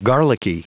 Prononciation du mot garlicky en anglais (fichier audio)
Prononciation du mot : garlicky